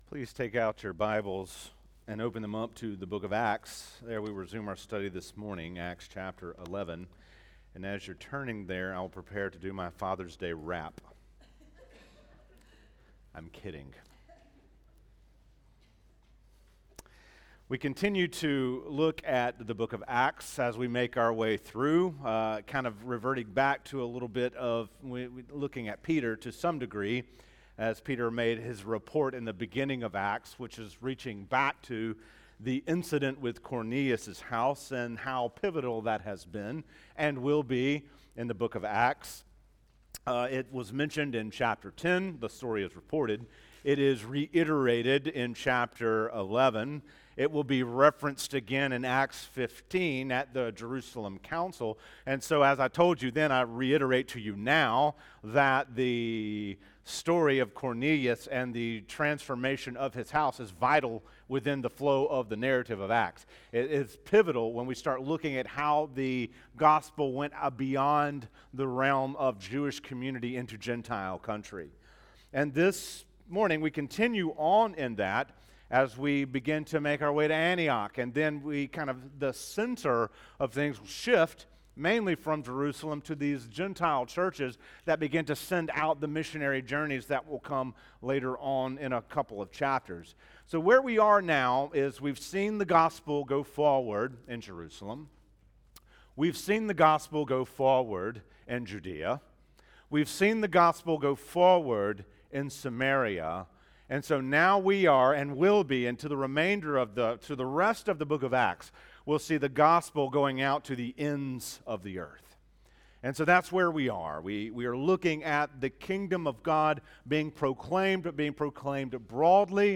We Saw the Grace of God » The Chapel Church of Gainesville, Florida